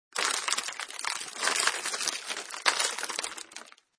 descargar sonido mp3 roca